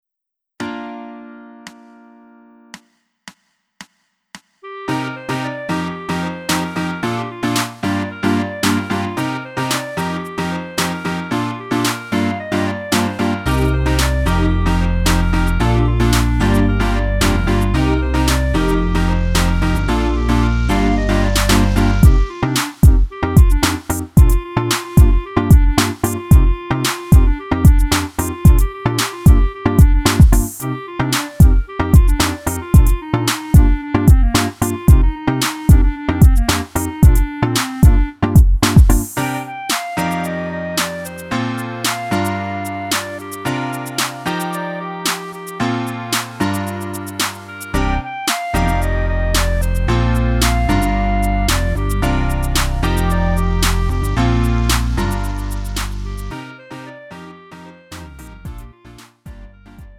음정 원키 3:51
장르 가요 구분 Lite MR
Lite MR은 저렴한 가격에 간단한 연습이나 취미용으로 활용할 수 있는 가벼운 반주입니다.